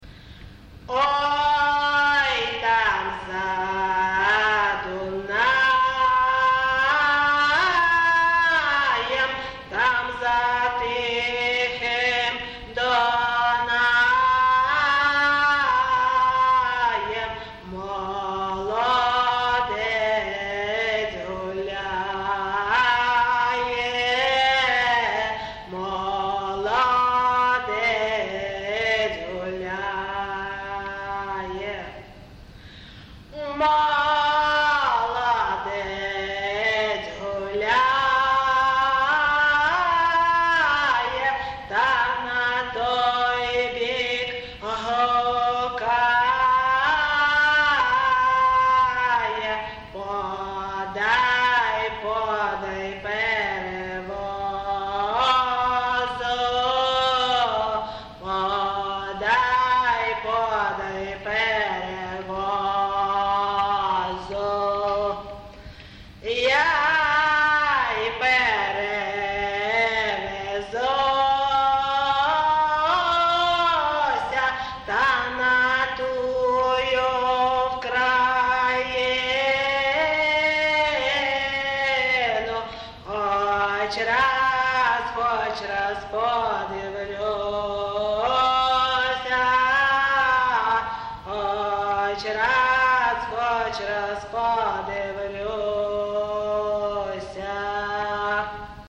ЖанрПісні з особистого та родинного життя
Місце записус. Ковалівка, Миргородський район, Полтавська обл., Україна, Полтавщина